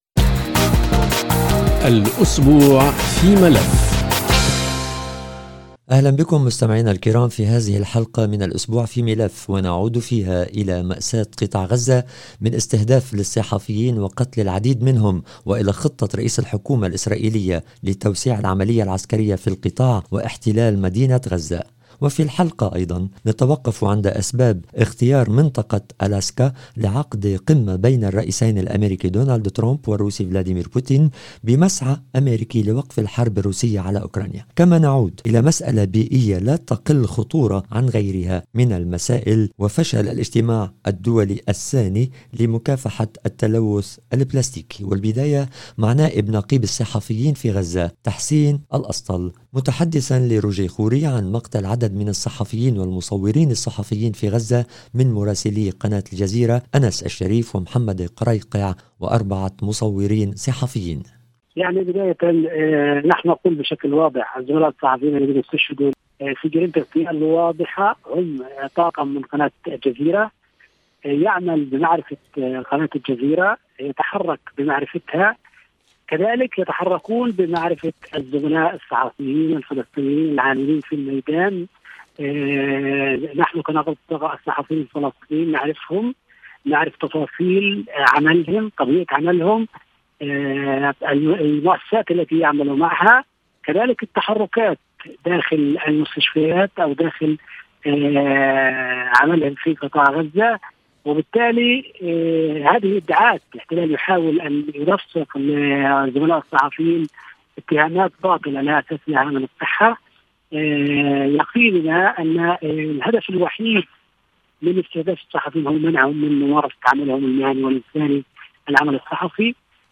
الاسبوع في ملف يستقبل عدداً من المسؤليين والخبراء في مجالات عدة خلال الاسبوع. وفي حلقة هذا الاسبوع من البرنامج تدور مع عدد من المتدخلين، وتتمحور حول الوضع في غزة، القمة الروسية الاميركية في الاسكا وفشل المساعي الدولية للمرة الثانية في التوصل لصيغة لمكافحة التلوث البلاستيكي.